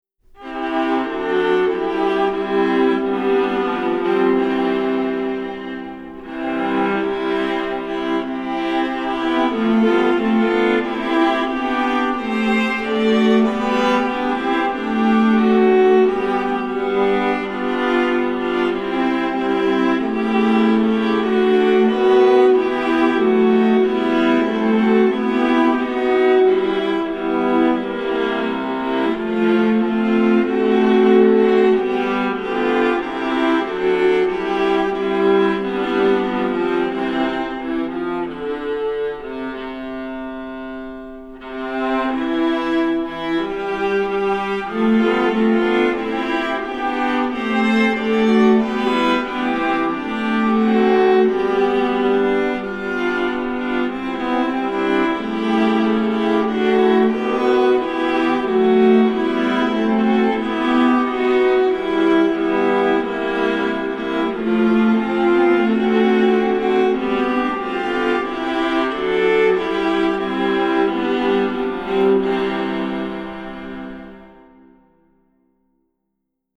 Voicing: 4 Viola